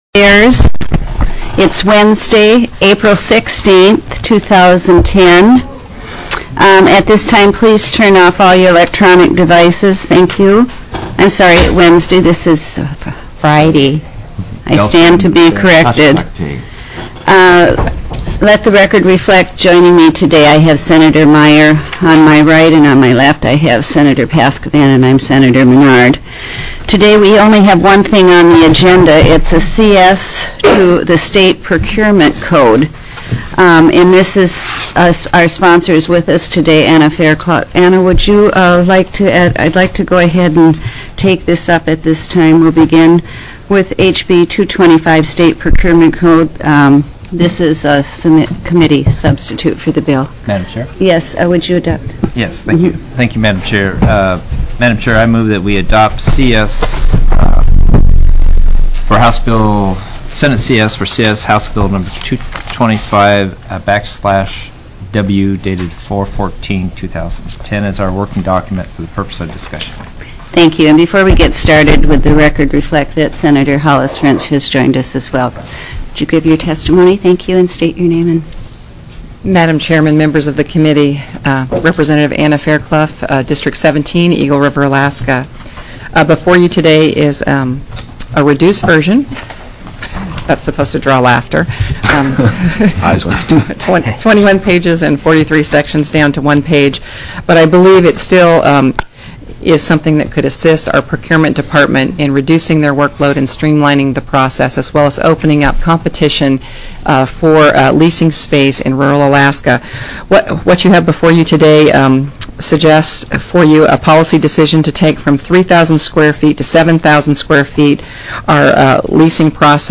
04/16/2010 08:45 AM Senate STATE AFFAIRS
+= HB 225 Heard & Held TELECONFERENCED